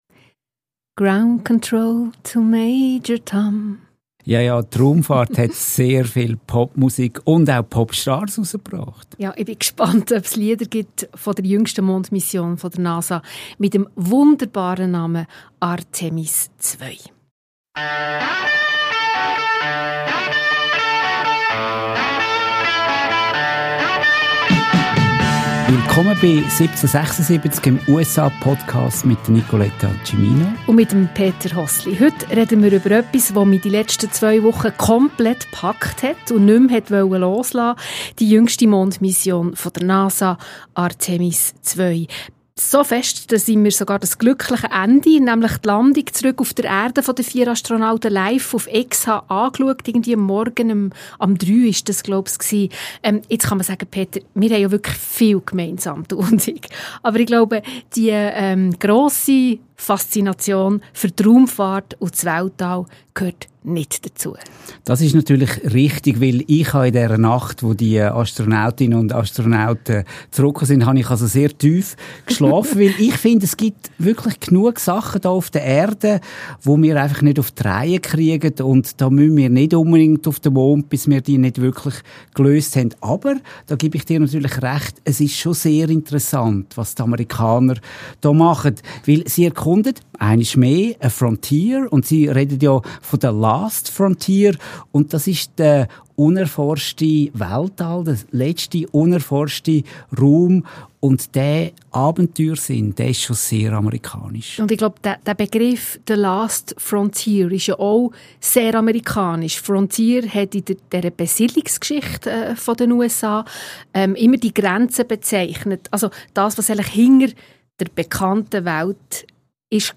Ein Gespräch über nationale Mythen, milliardenschwere Raumfahrtträume, private Tech-Milliardäre und die Rückkehr eines uralten amerikanischen Versprechens: die Eroberung der «Last Frontier».